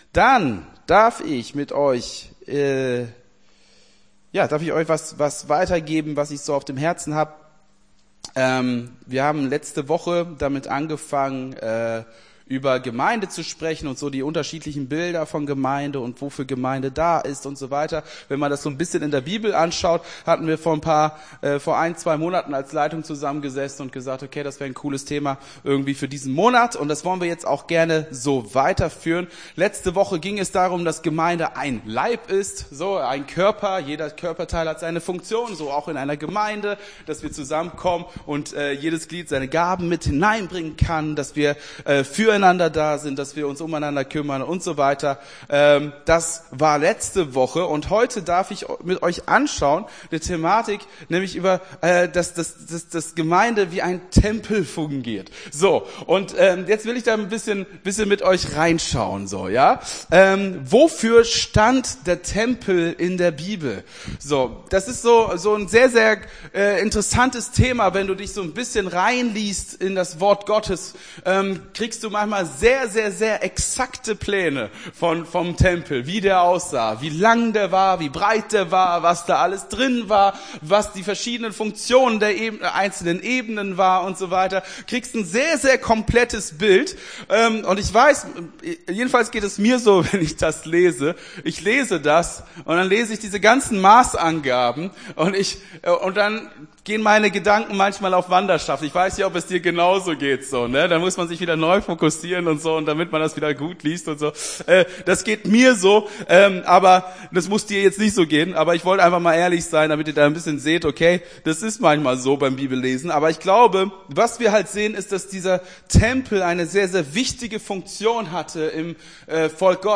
Gottesdienst 12.11.23 - FCG Hagen